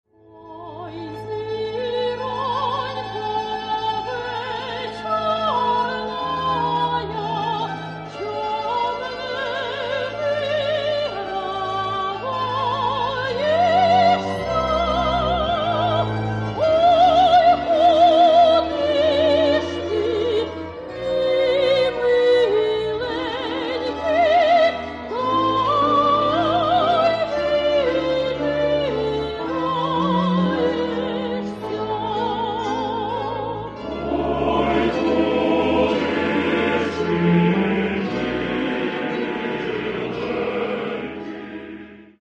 Каталог -> Класична -> Опера та вокал